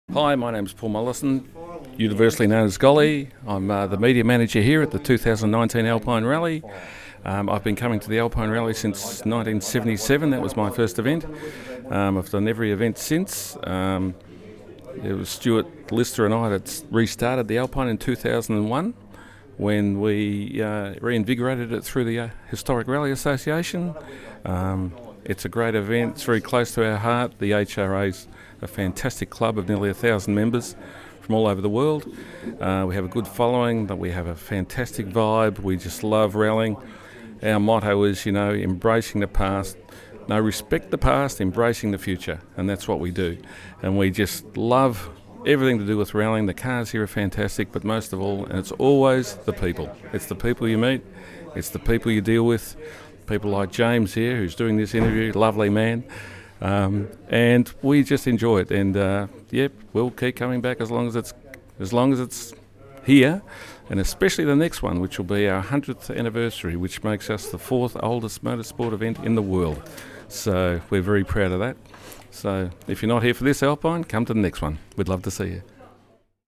Alpine Rallyof East Gipplsland 2019 - Radio Grabs free to use without attribution on any media
RADIO GRABSFree to use without attribution